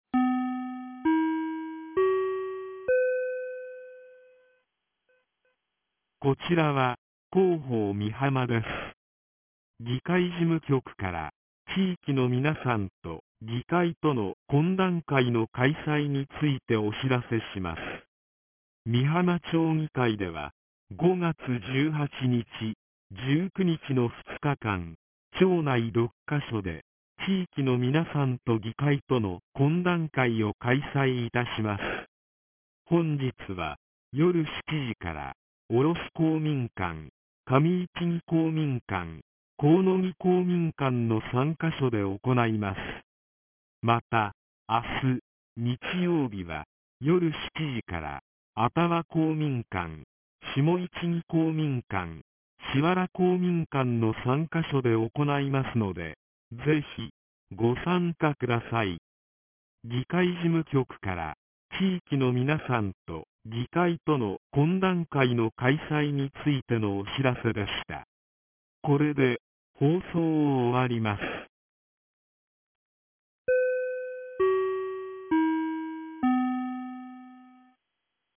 ■防災行政無線情報■